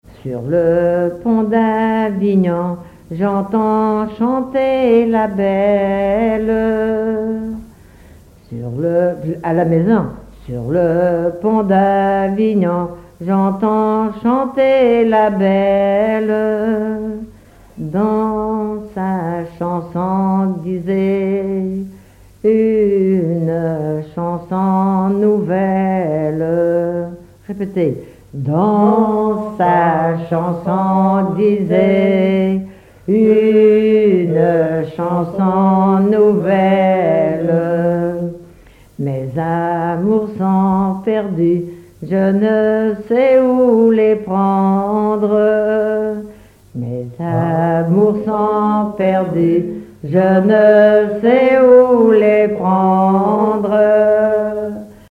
collecte en Vendée
Témoignages et chansons traditionnelles
Pièce musicale inédite